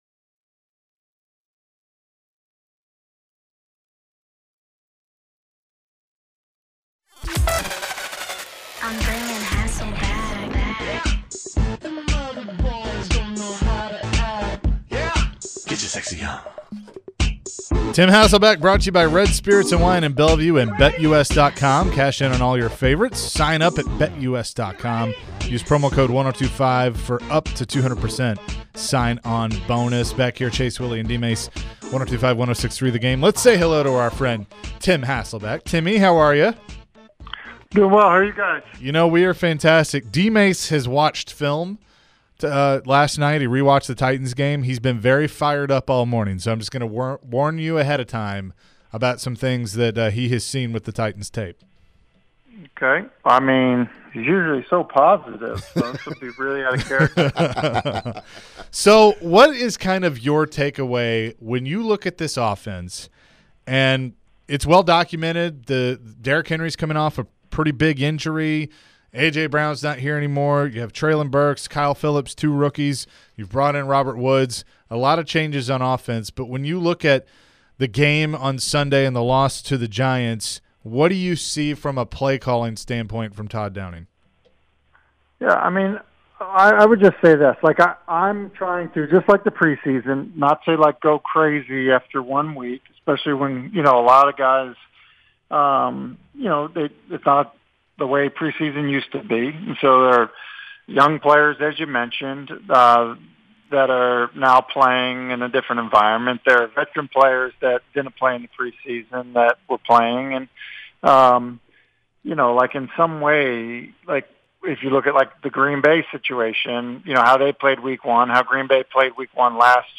Tim Hasselbeck Full Interview (09-13-22)